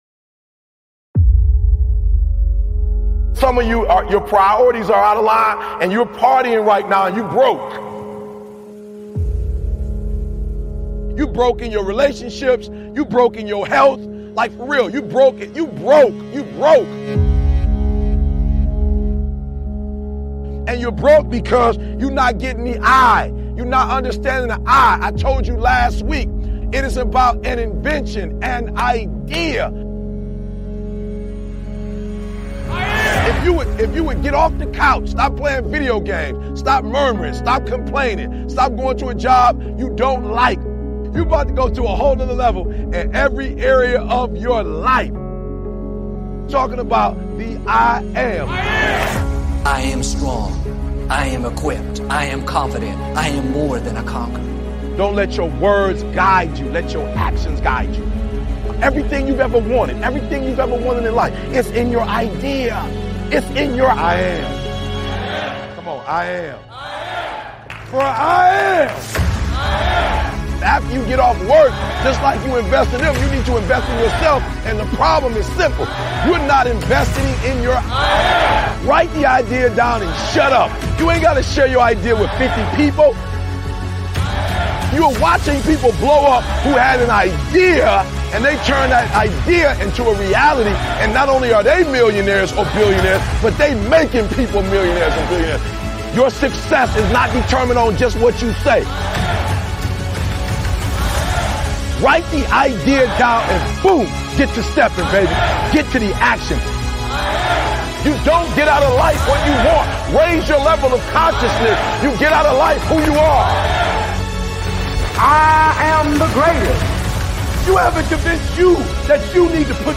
Speaker: Eric Thomas